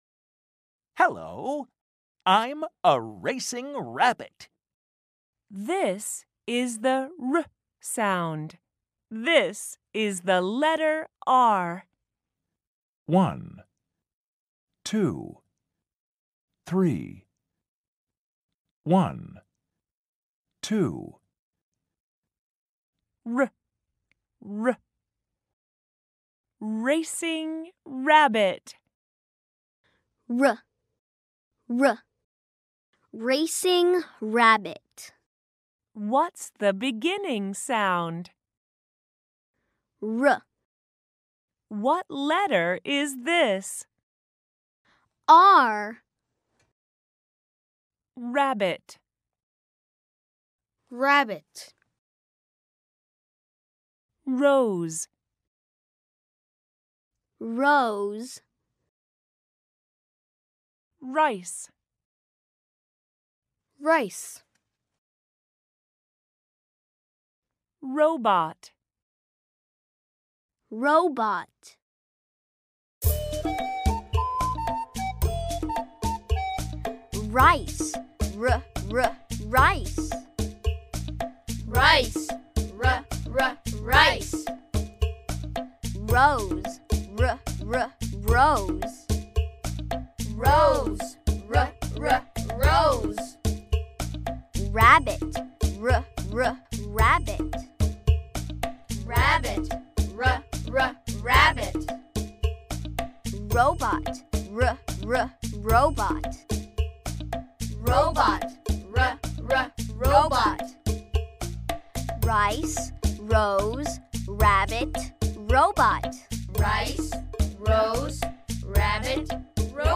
Today we're going to learn the sound of the letter R. The letter R makes the /r/ sound, which is produced by curling the tip of your tongue towards the roof of your mouth without touching it. Practice with me and learn some words that start with R, like rabbit, rose, and robot.